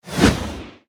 archer_skill_spyraledge_03_kick.ogg